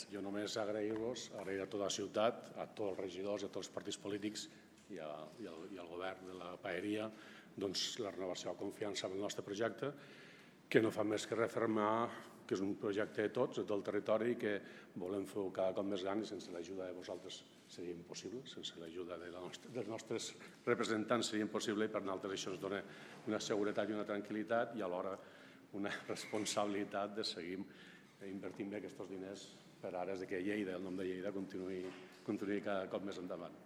Tall de veu de l'alcalde de Lleida, Miquel Pueyo, sobre el conveni amb el Força Lleida